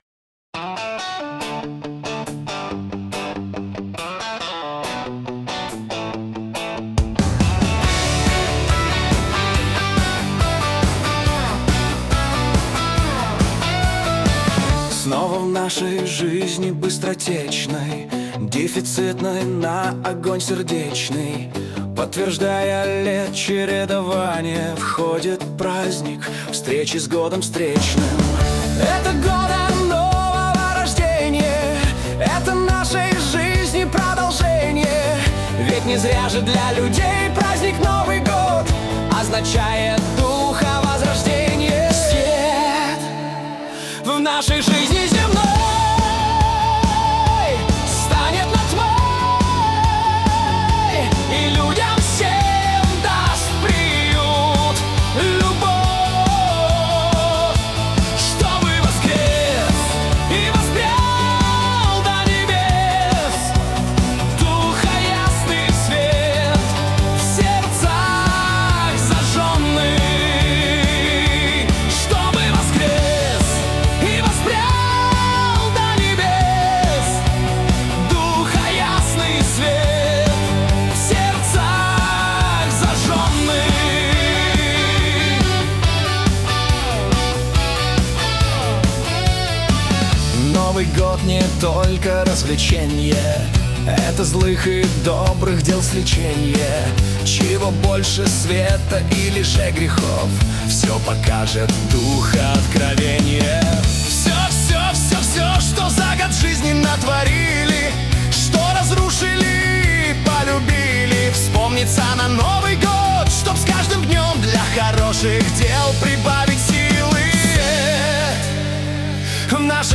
кавер-версия
Для Медитаций